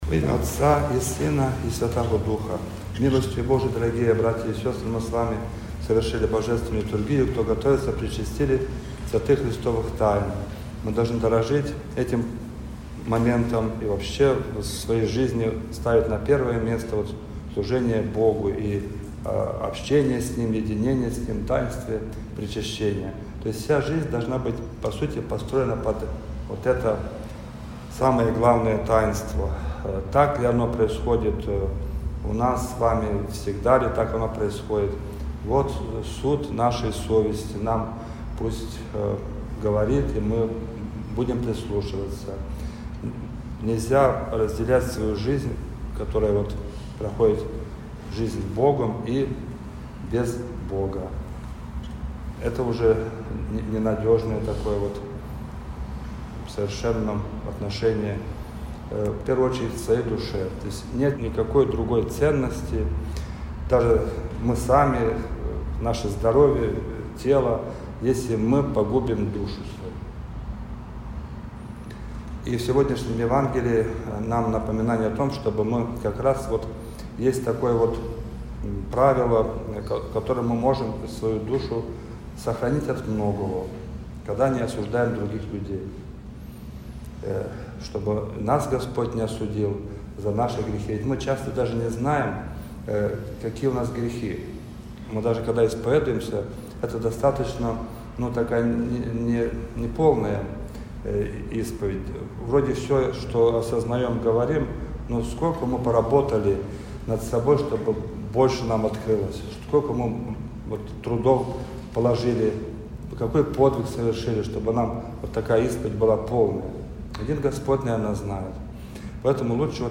Проповедь.mp3